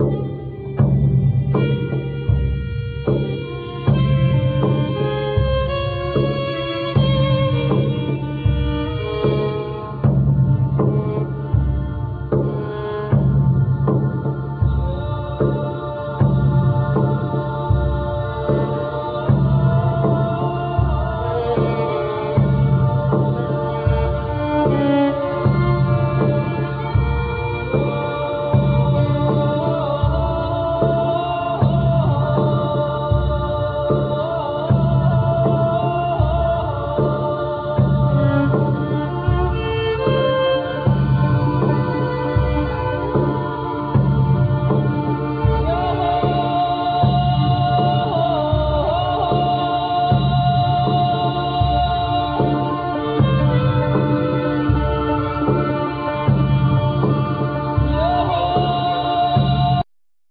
Accordion,Clarinet,Piano,Percussion,Computer
Violin
Doudouk
Voice
Viola
Cello
Double Bass